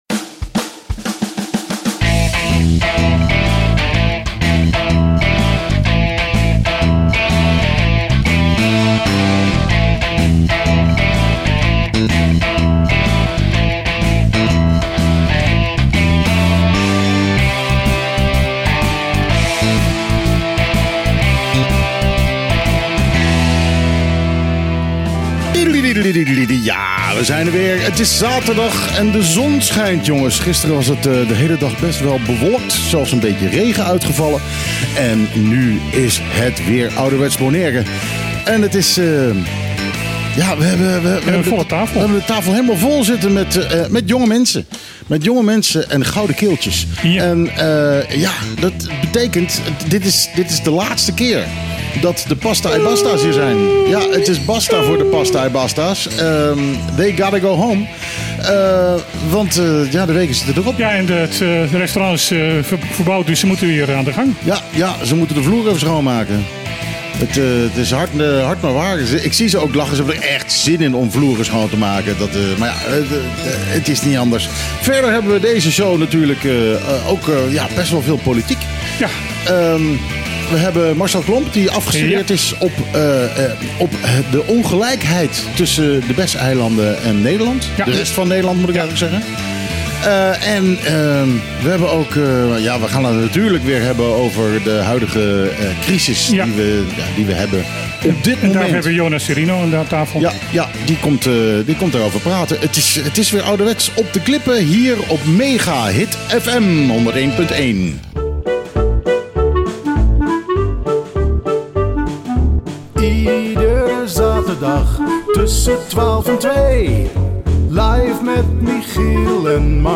De integrale opname van de uitzending van het radioprogramma Op de Klippen.